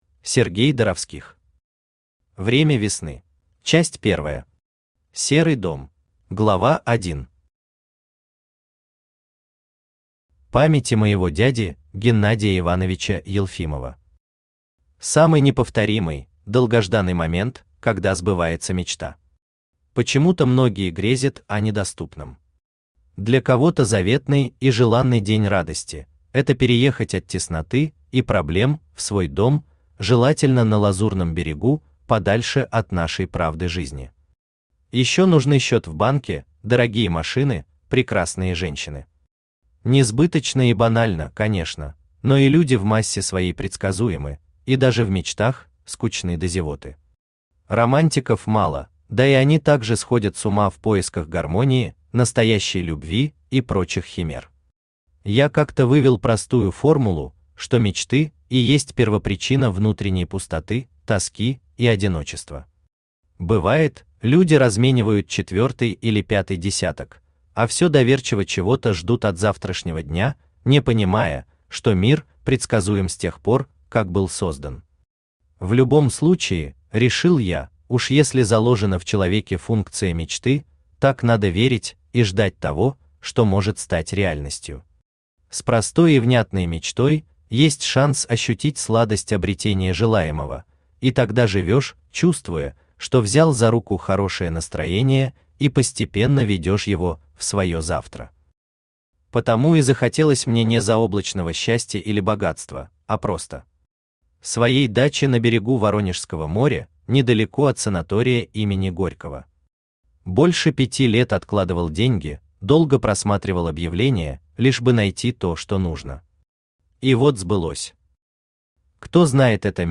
Aудиокнига Время весны Автор Сергей Владимирович Доровских Читает аудиокнигу Авточтец ЛитРес.